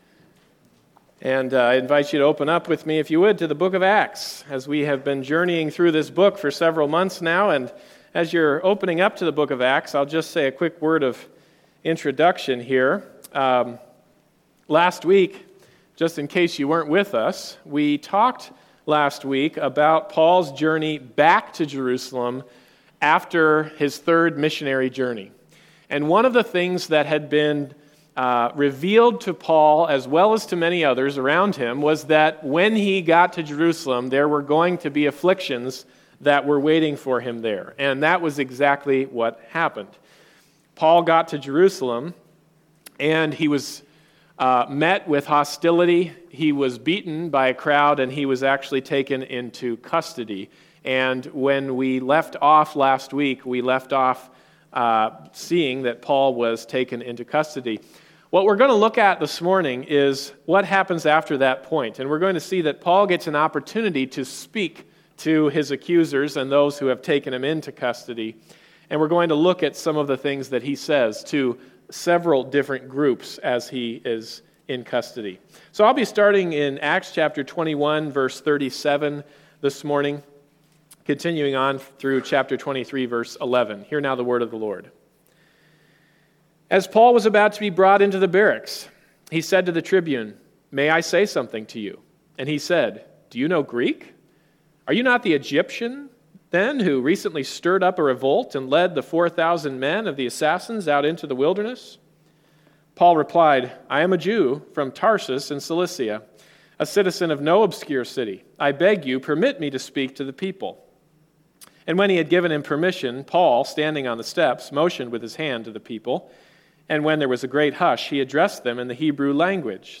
Bible Text: Acts 21:37-23:11 | Preacher